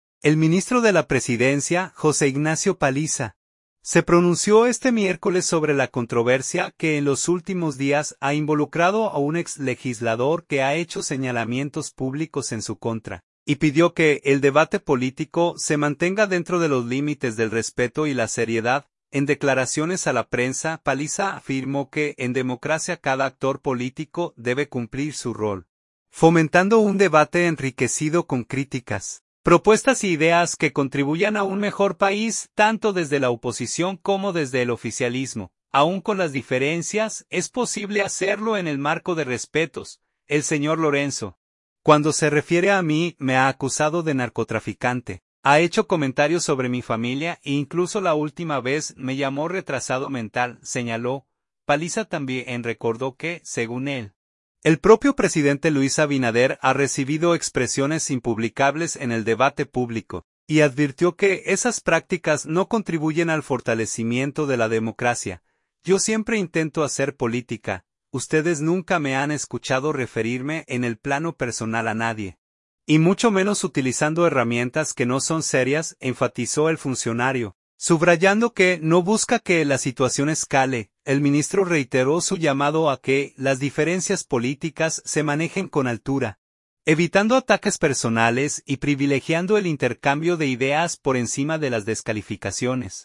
En declaraciones a la prensa, Paliza afirmó que en democracia cada actor político debe cumplir su rol, fomentando un debate enriquecido con críticas, propuestas e ideas que contribuyan a un mejor país, tanto desde la oposición como desde el oficialismo.